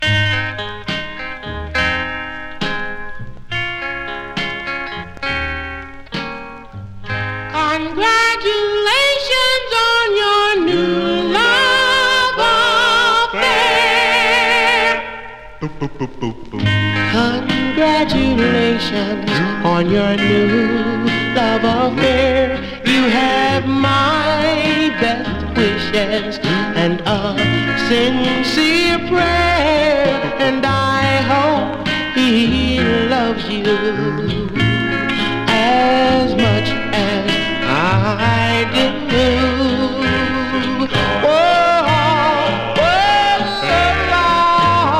Rock’N’Roll, Doo Wop　USA　12inchレコード　33rpm　Mono